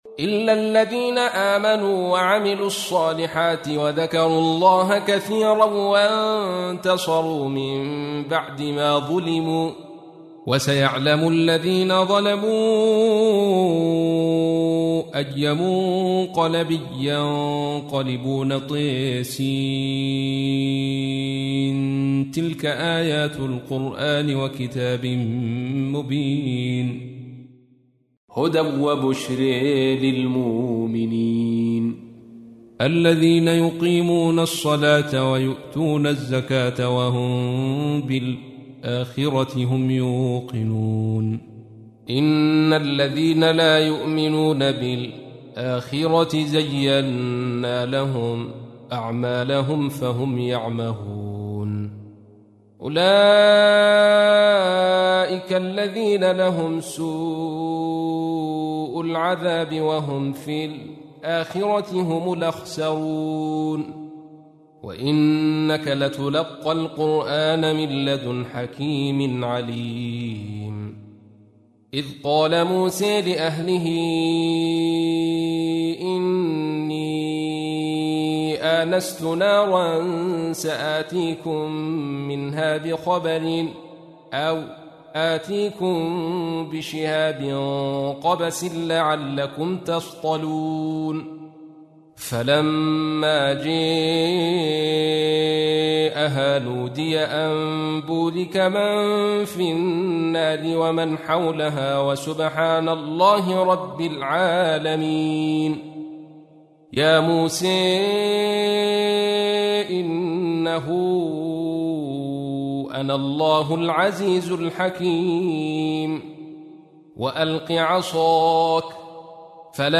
تحميل : 27. سورة النمل / القارئ عبد الرشيد صوفي / القرآن الكريم / موقع يا حسين